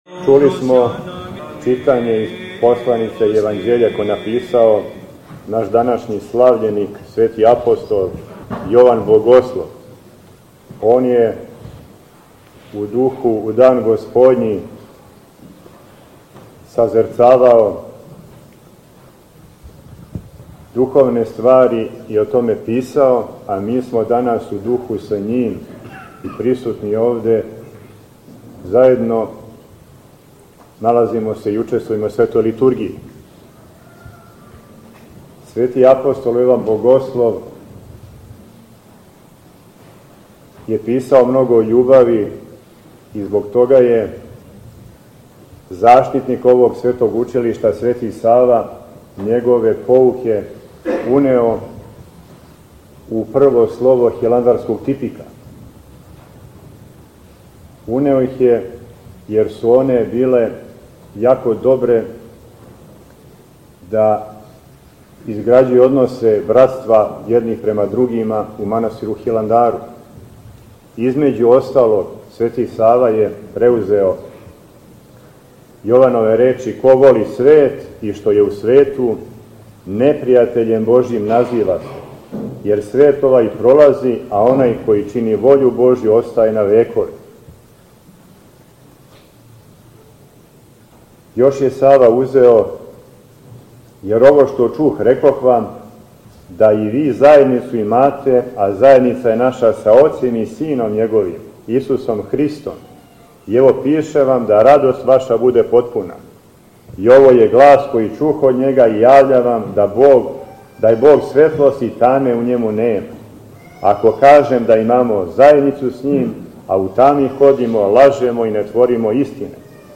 У оквиру серијала „Са амвона“, доносимо звучни запис беседе коју је Његово Преосвештенство Епископ моравички г. Тихон, викар патријарха српског, изговорио у дан свештеног спомена Светог апостола и јеванђелиста Јована Богослова, 26. септембра / 9. октобра 2025. године. Епископ Тихон је беседио на светој Литургији у параклису Светог апостола и јеванђелиста Јована Богослова у здању Православног богословског Факултета Универзитета у Београду.